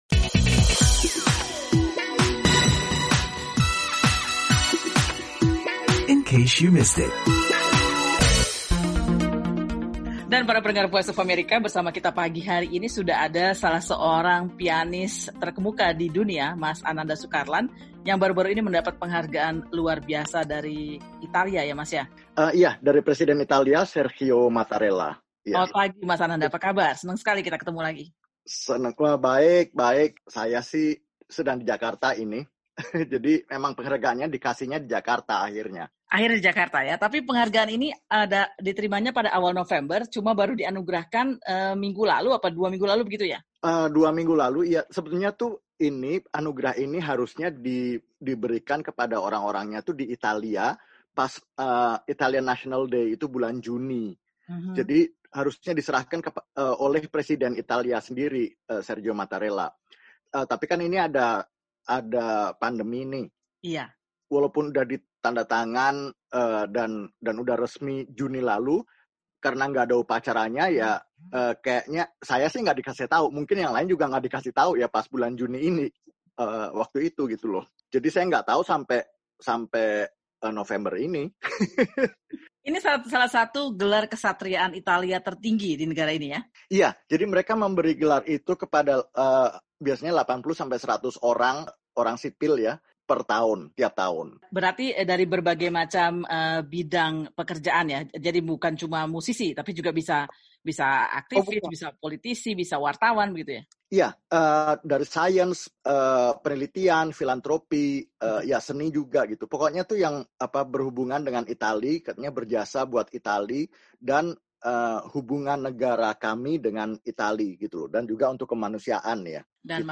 mengobrol dengan pianis Indonesia, Ananda Sukarlan